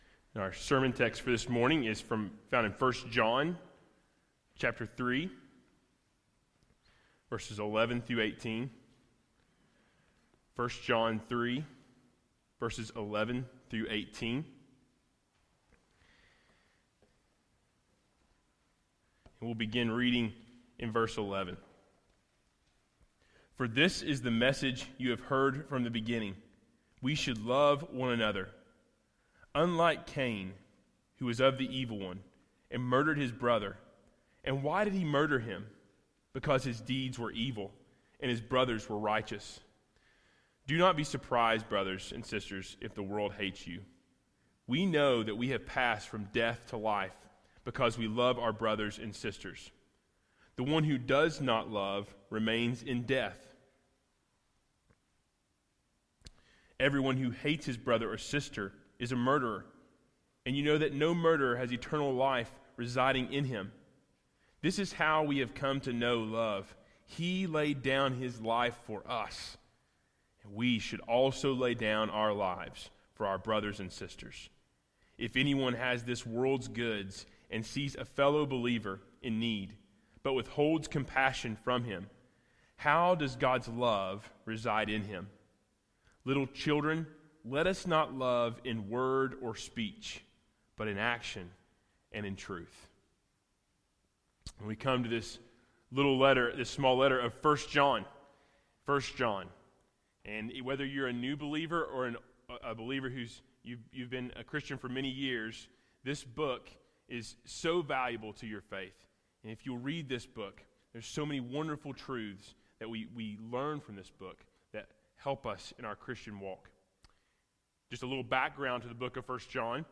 Sermon Audio 2018 October 21